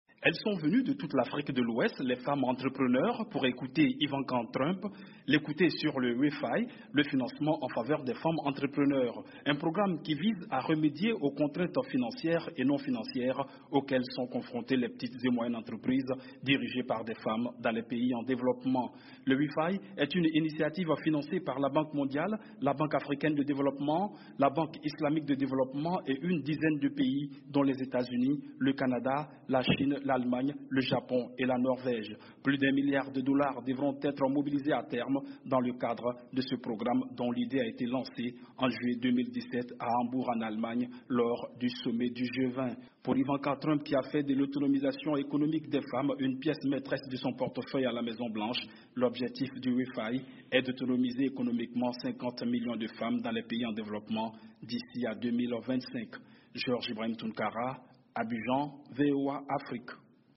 Ivanka Trump, fille du président américain et conseillère à la Maison Blanche a pris part ce mercredi à une Conférence consacrée au financement des femmes entrepreneurs en Afrique de l'Ouest, qui s'est tenue à Abidjan. Reportage